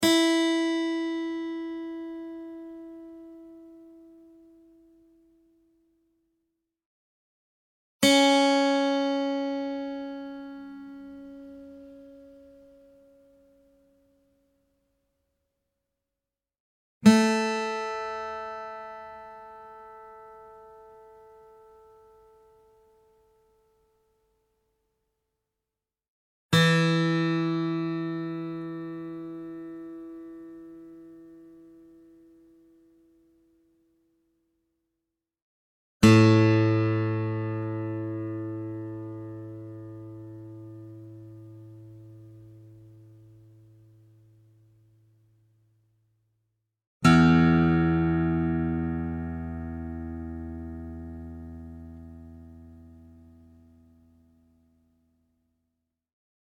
Real acoustic guitar sounds in Open A Tuning
Guitar Tuning Sounds